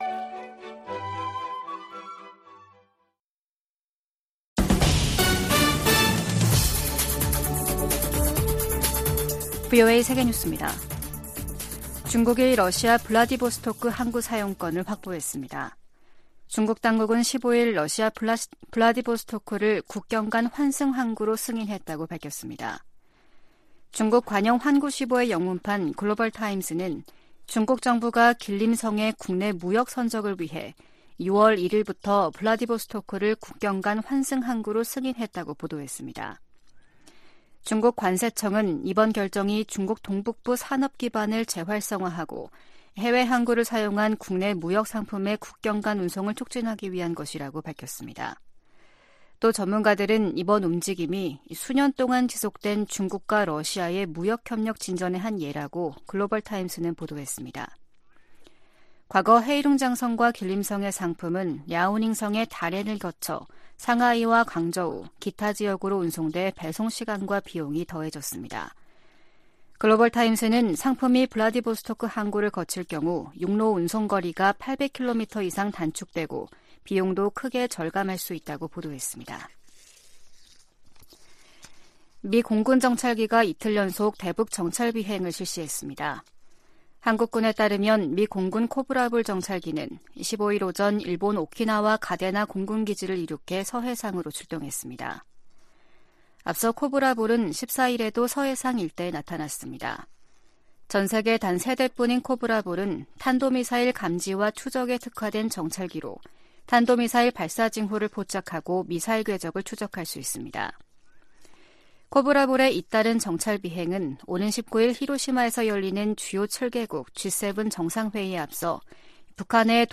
VOA 한국어 아침 뉴스 프로그램 '워싱턴 뉴스 광장' 2023년 5월 16일 방송입니다. 윤석열 한국 대통령이 일본에서 열리는 G7 정상회의를 계기로 서방 주요국 지도자들과 회담하고 강한 대북 메시지를 낼 것으로 보입니다. G7 정상회의에 참석하는 캐나다와 유럽연합(EU), 독일 정상들이 잇따라 한국을 방문해 윤 대통령과 회담합니다. 북한은 다양한 핵탄두 개발을 위해 추가 핵실험을 하게 될 것이라고 미국의 전문가가 밝혔습니다.